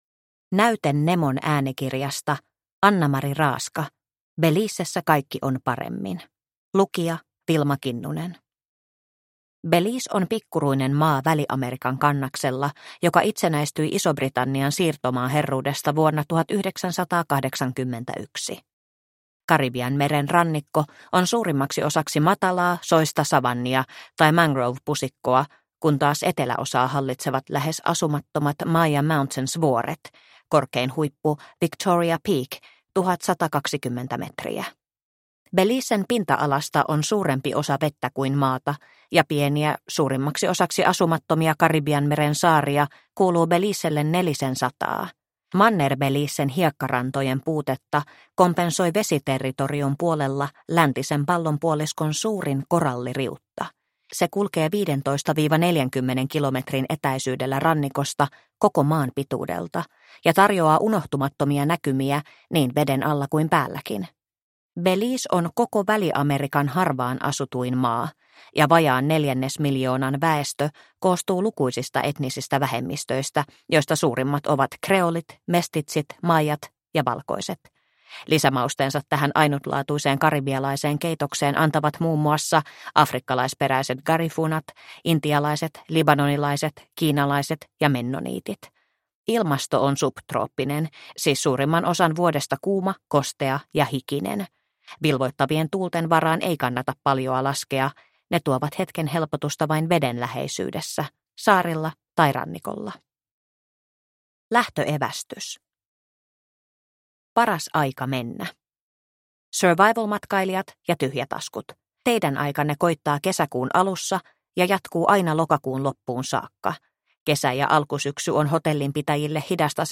Belizessä kaikki on paremmin – Ljudbok – Laddas ner